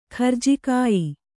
♪ kharjikāyi